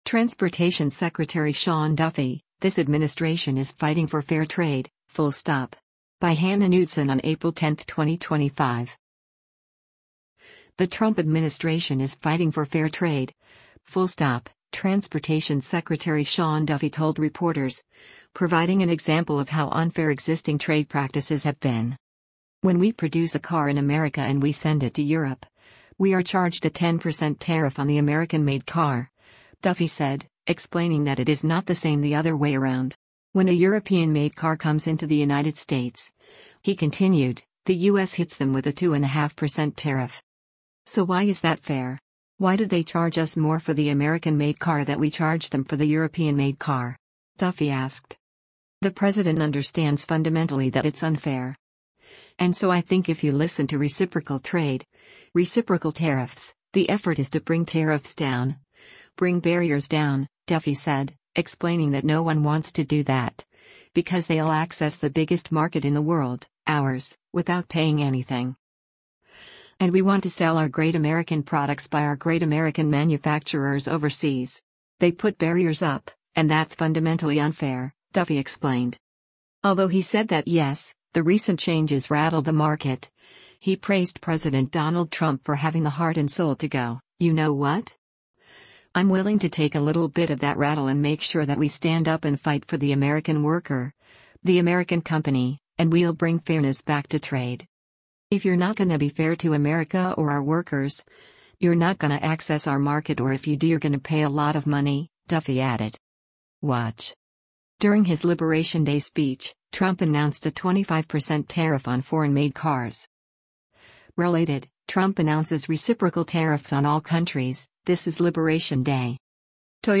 The Trump administration is “fighting for fair trade — full stop,” Transportation Secretary Sean Duffy told reporters, providing an example of how unfair existing trade practices have been.